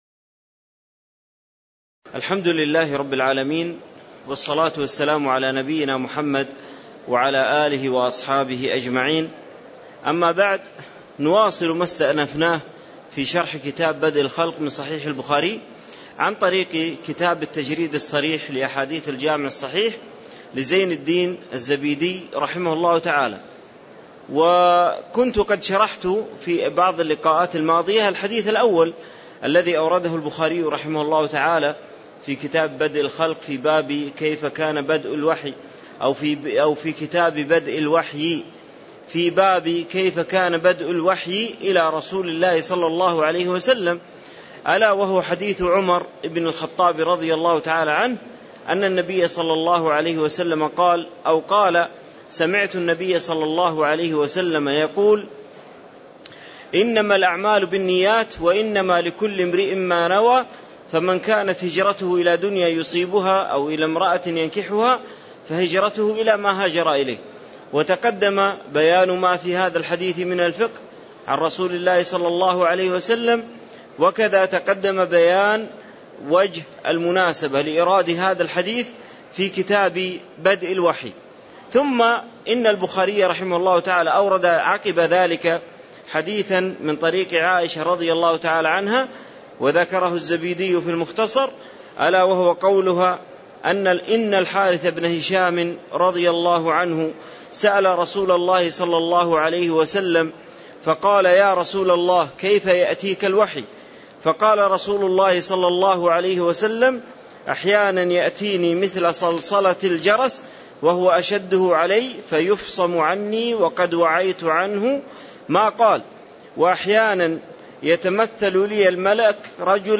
تاريخ النشر ٢٨ ذو الحجة ١٤٣٧ هـ المكان: المسجد النبوي الشيخ